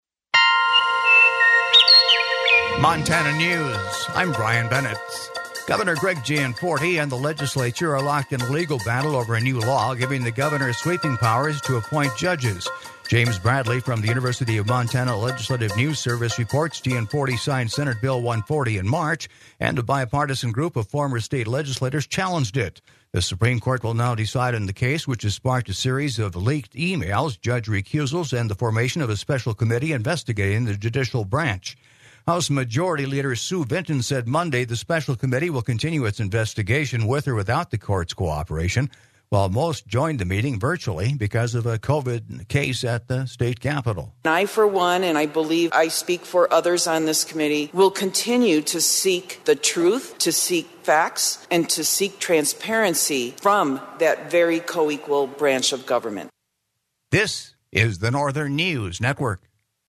In: News Headlines